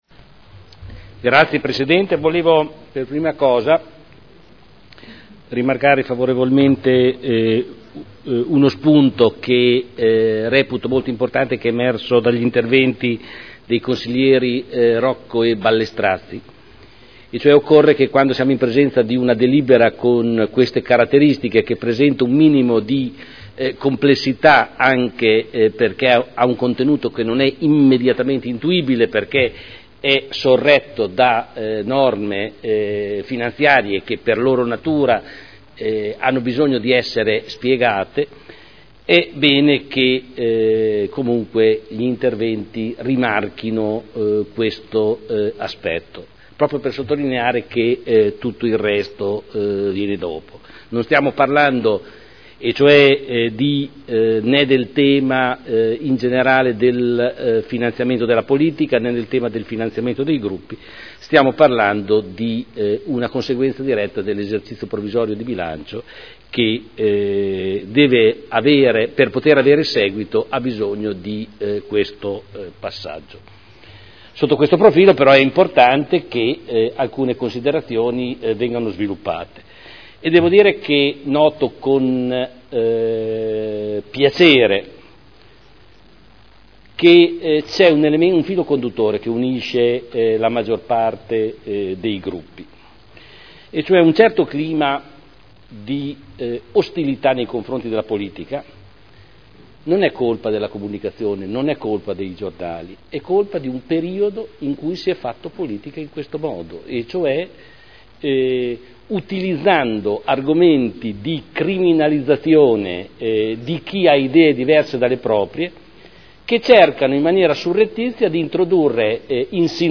Giorgio Pighi — Sito Audio Consiglio Comunale